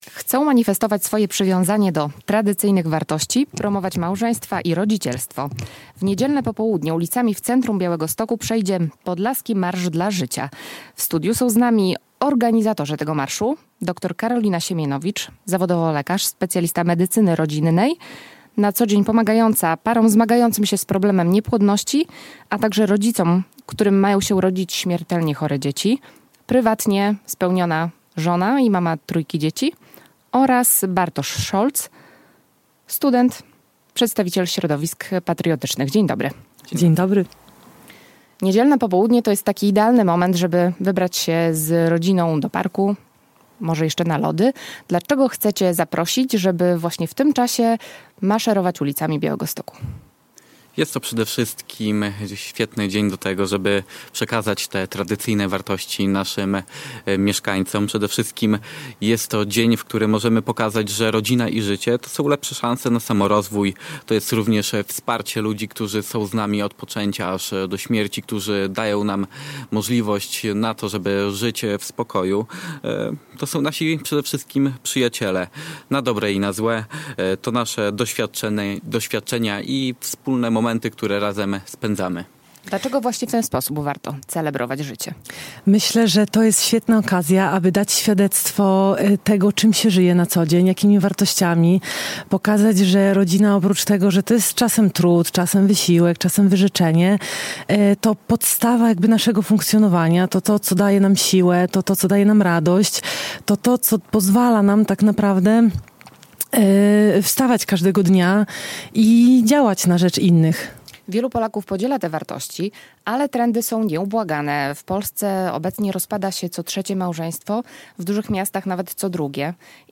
organizatorzy Podlaskiego Marszu dla Życia